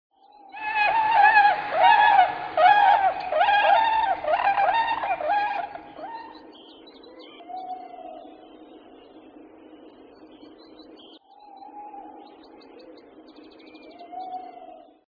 Schwan
schwan.mp3